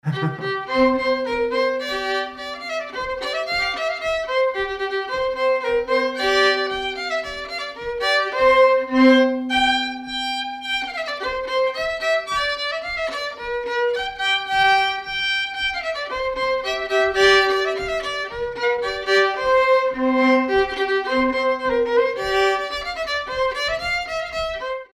Polka
danse : polka
circonstance : bal, dancerie
Pièce musicale inédite